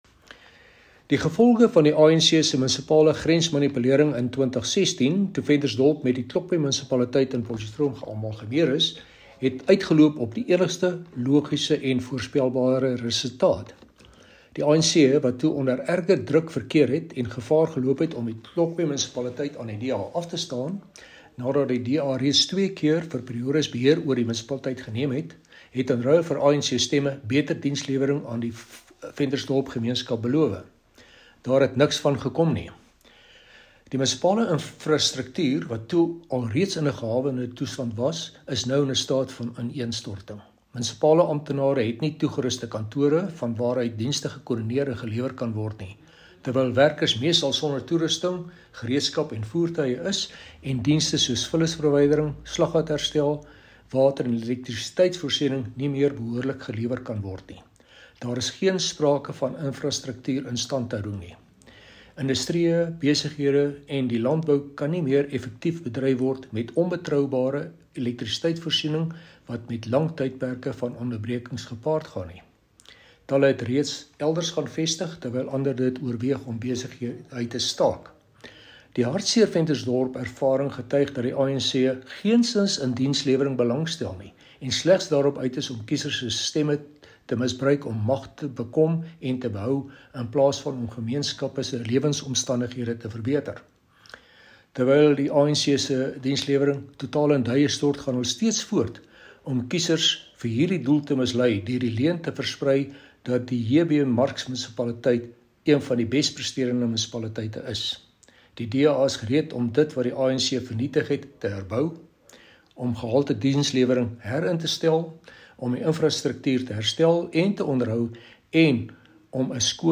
Note to Editors: Please find attached soundbites in
Afrikaans by Cllr Chris Hattingh.